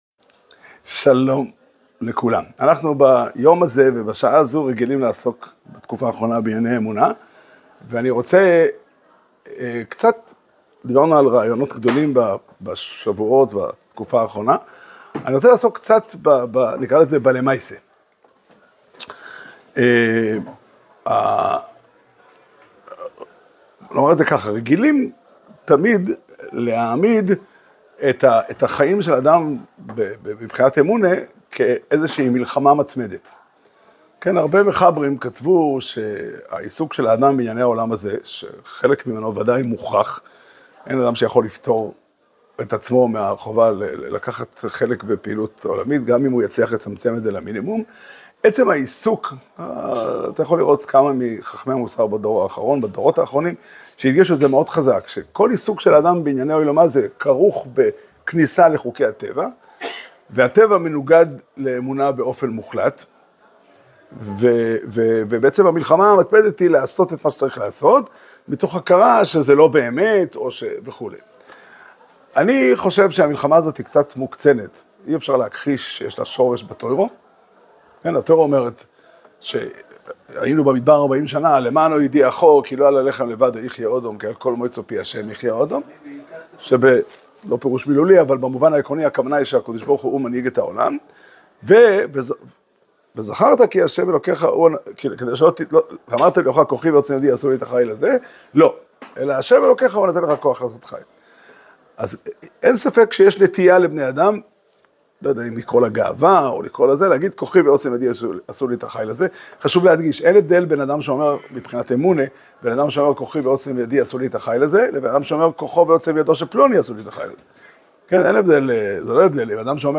שיעור שנמסר בבית המדרש פתחי עולם בתאריך י"ג שבט תשפ"ה